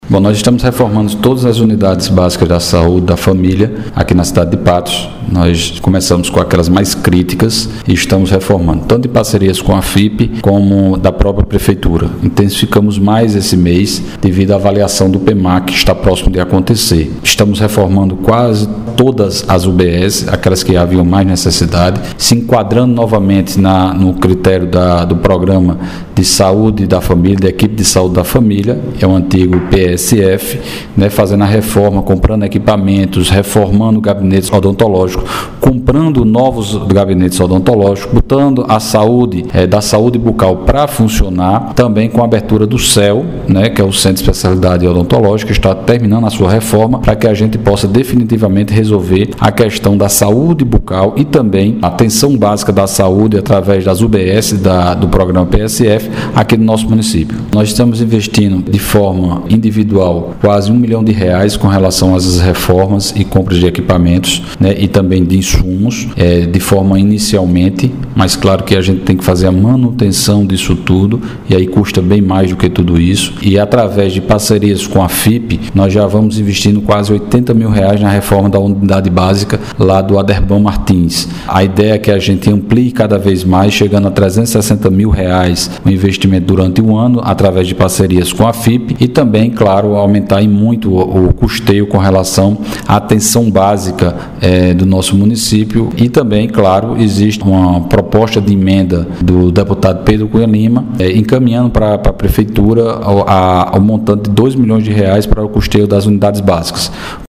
SONORA COM DINALDINHO –